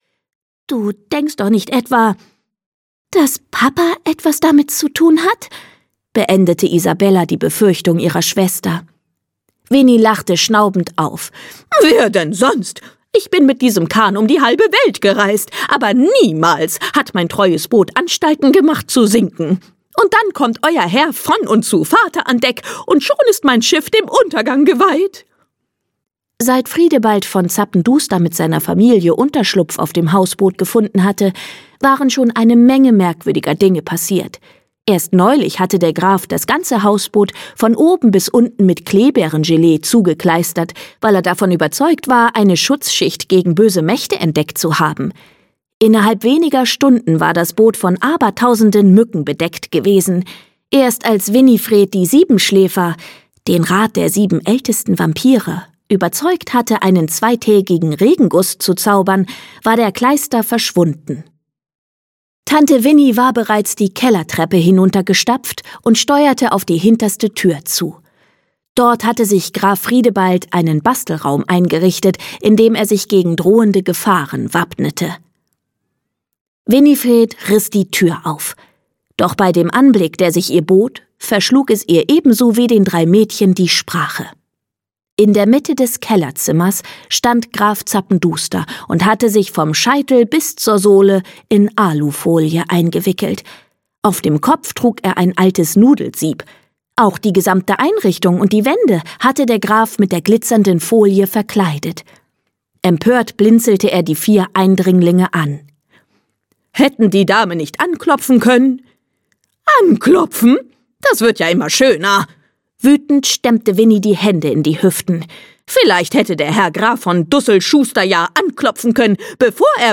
Polly Schlottermotz 4: Walfisch Ahoi! - Lucy Astner - Hörbuch